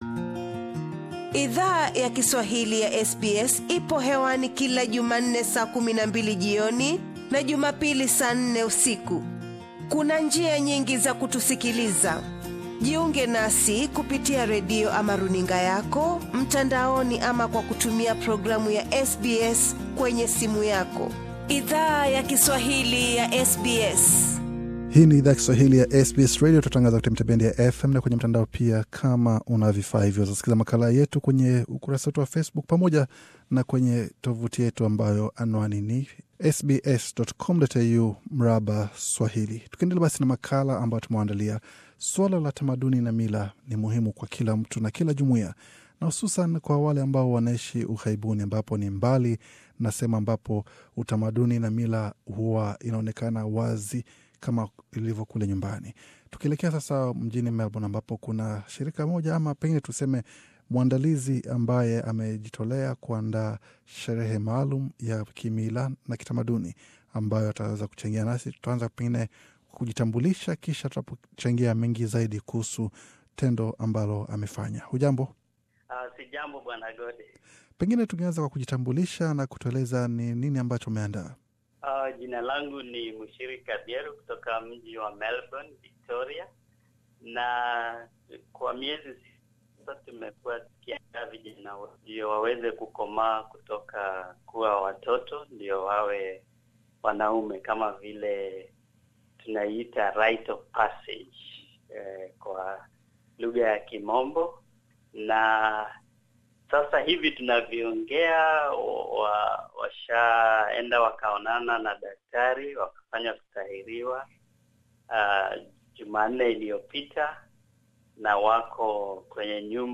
SBS Swahili spoke with a group of young people of Kenyan heritage who have recently participated in a traditional ritual of crossing over into adulthood through circumcision.